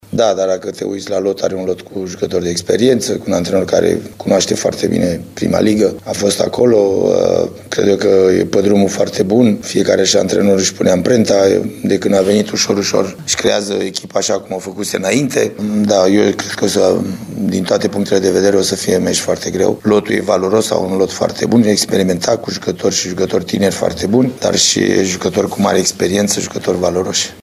Cu toate acestea, Gheorghe Hagi nu consideră că „Bătrâna Doamnă” își merită locul din clasament: